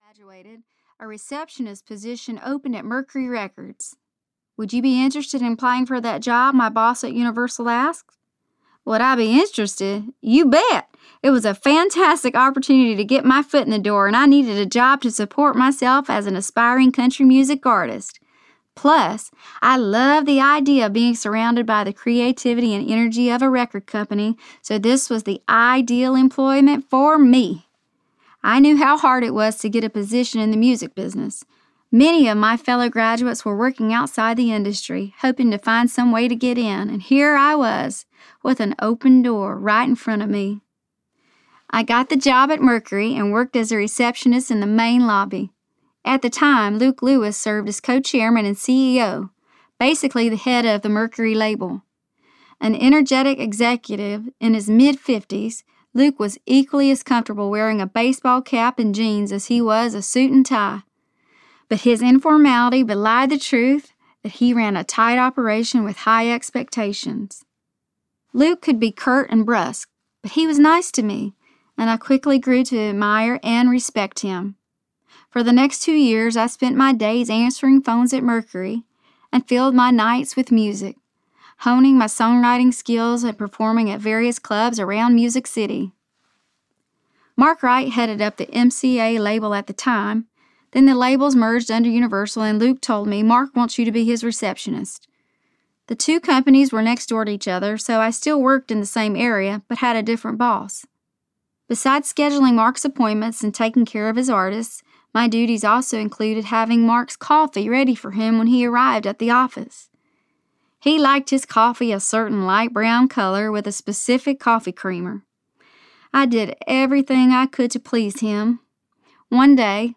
Beauty in the Breakdown Audiobook